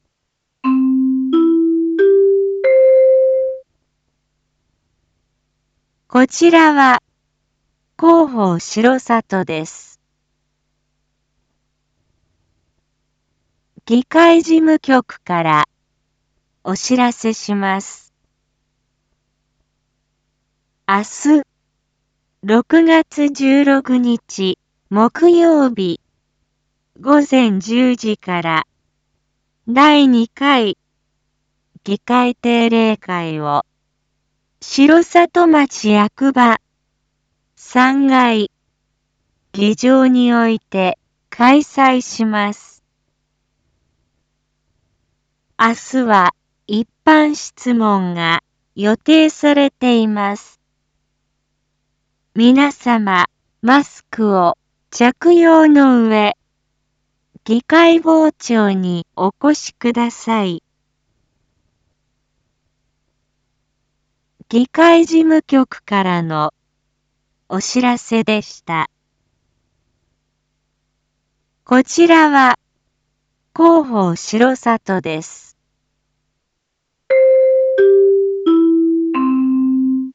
一般放送情報
Back Home 一般放送情報 音声放送 再生 一般放送情報 登録日時：2022-06-15 19:01:21 タイトル：R4.6.15 19時放送分 インフォメーション：こちらは広報しろさとです。